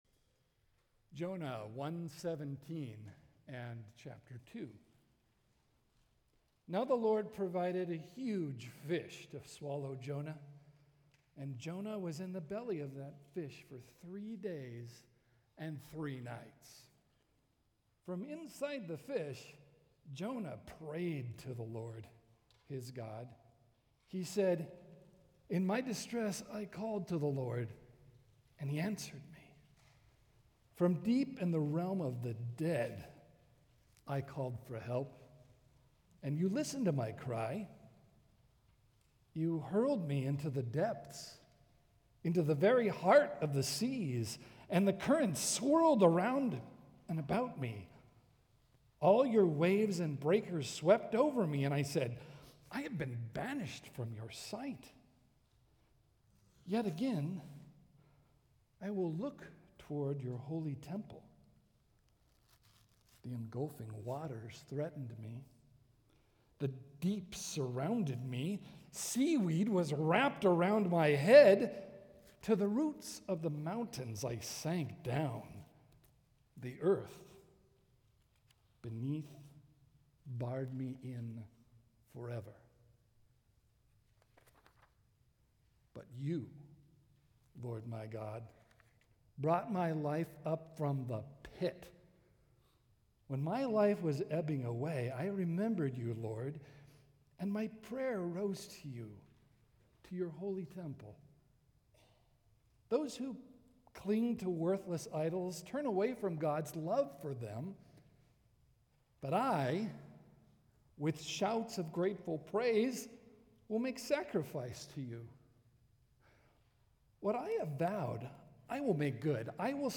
PCC Sermons